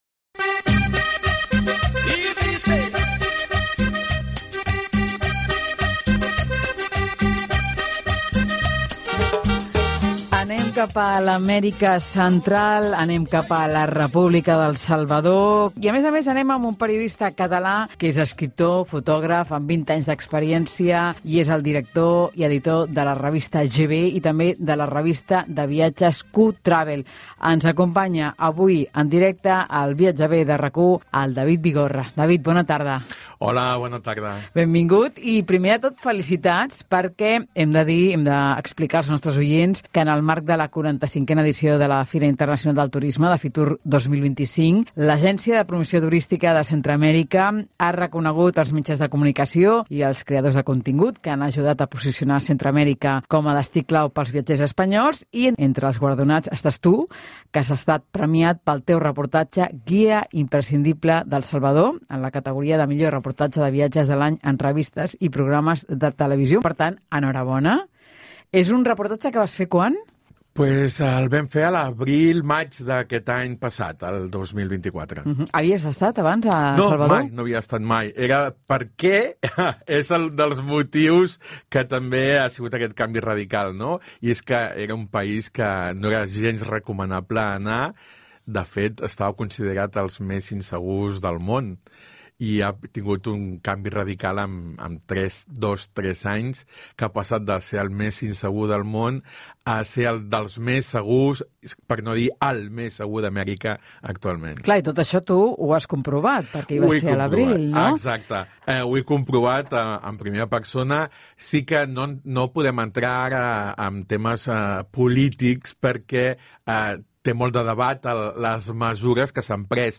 En esta ruta radiofónica de casi una hora de conversación, descubriremos: